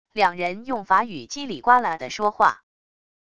两人用法语叽里呱啦的说话wav音频